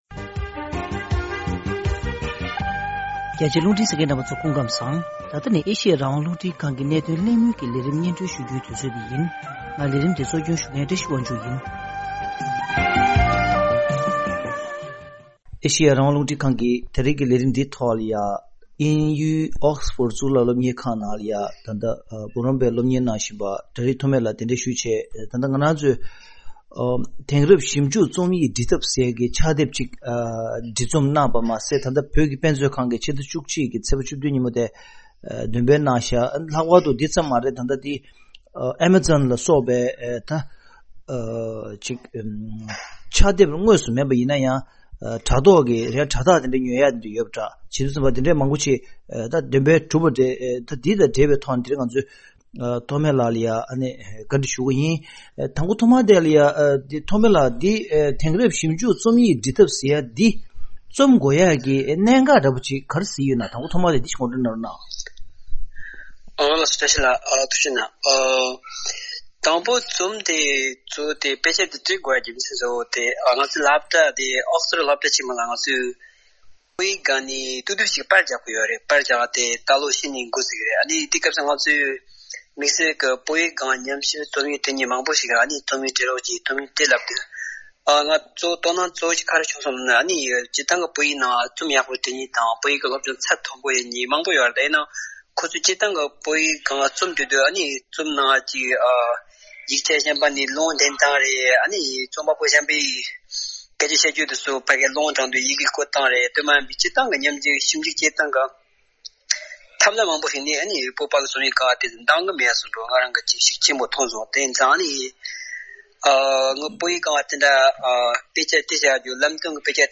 གླེང་མོལ